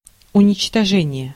Ääntäminen
IPA: /ʊnʲɪt͡ɕtɐˈʐɛnʲɪjə/